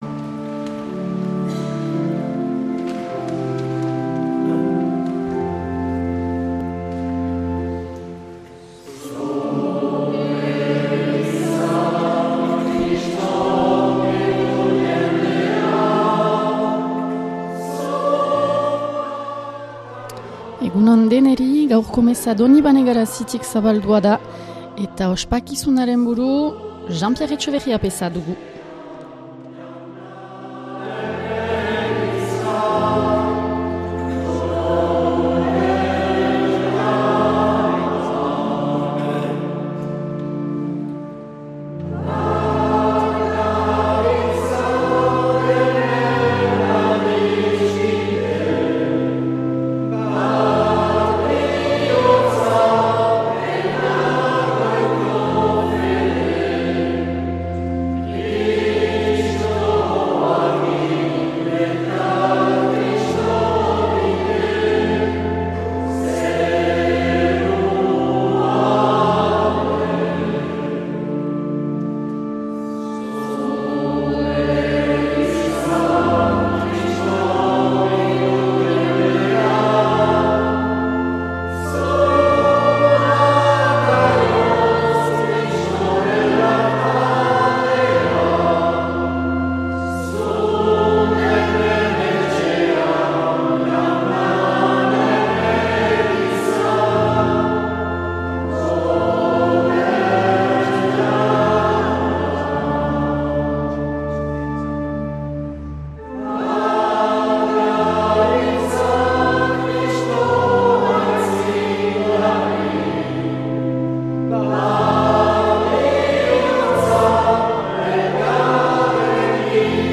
2025-11-09 Latraneko Elizaren besta - Garazi